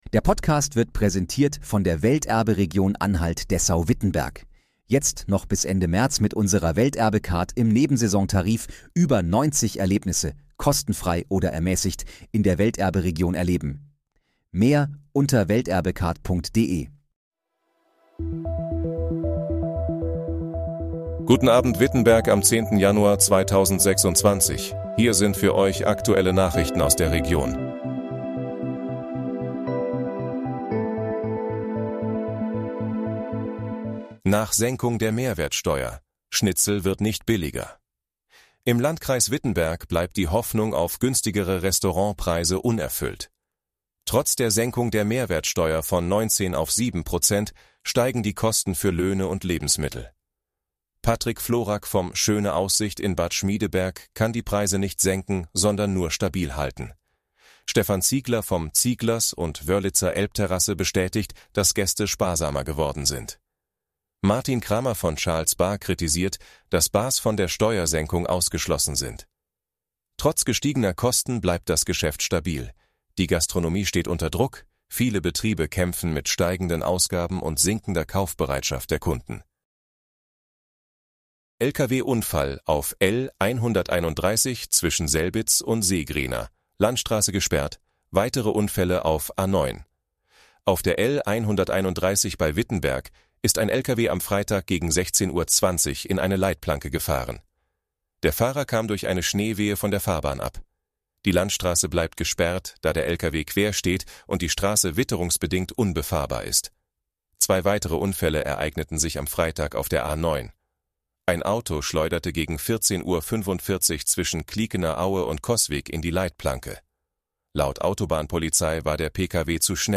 Guten Abend, Wittenberg: Aktuelle Nachrichten vom 10.01.2026, erstellt mit KI-Unterstützung
Nachrichten